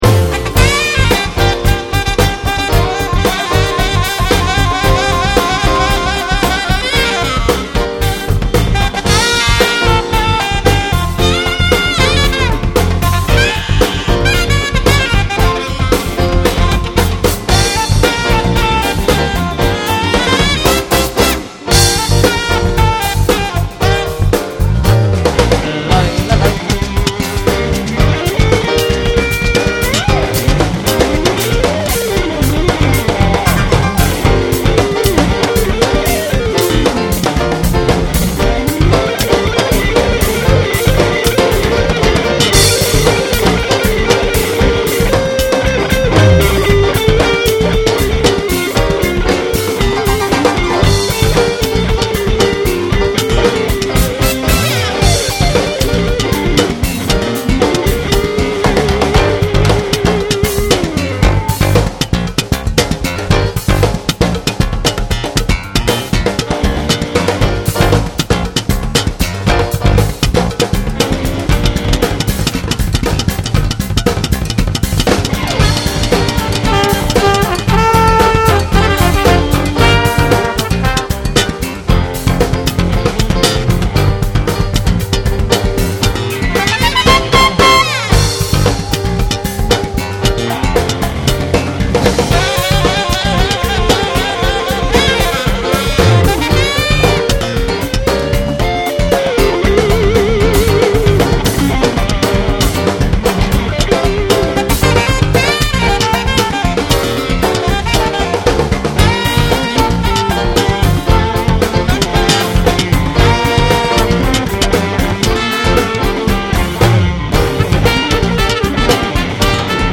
Some recordings may be clearer than others.